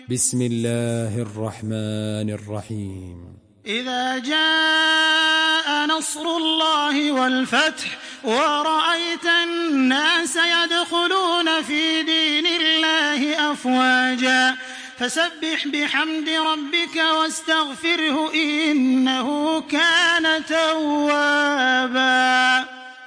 تحميل سورة النصر بصوت تراويح الحرم المكي 1426
مرتل